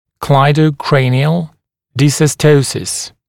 [ˌklaɪdəu’kreɪnɪəl ˌdɪsɔs’təusɪs][ˌклайдоу’крэйниэл ˌдисос’тоусис]ключично-черепной дизостоз